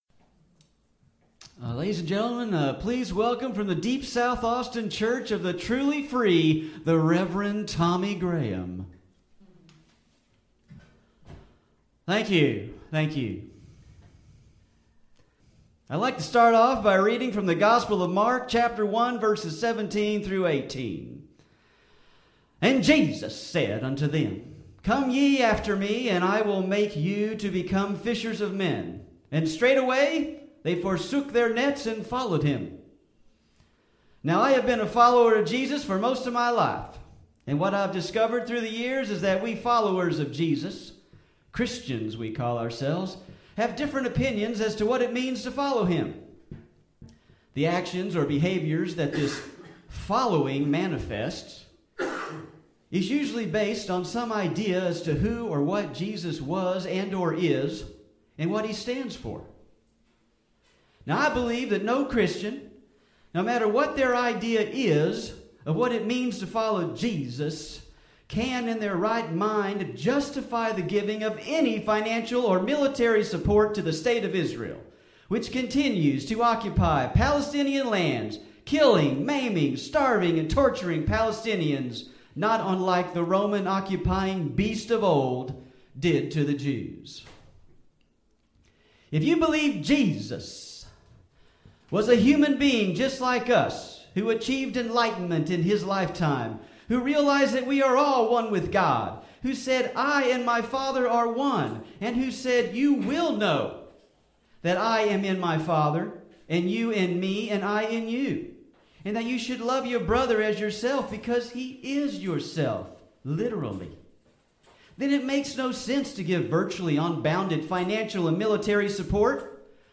Liberty Stage at Brave New Books, Austin, TX, 6/27/2012